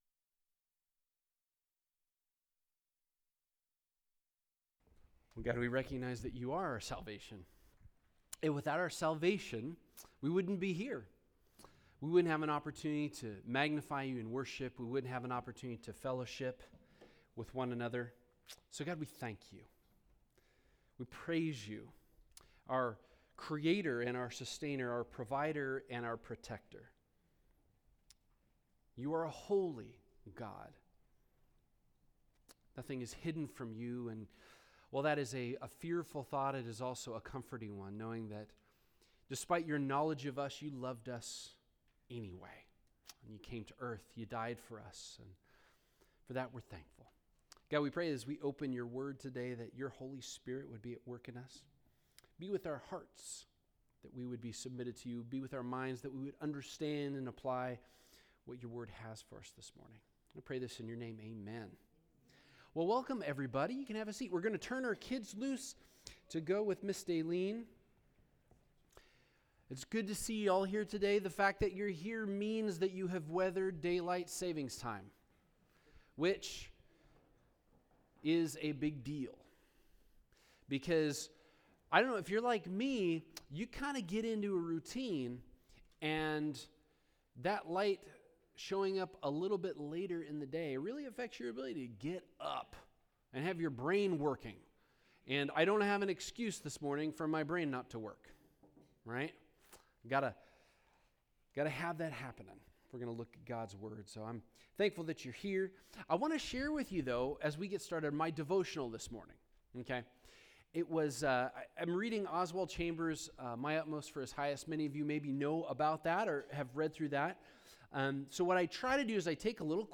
3-14-21-Sermon.mp3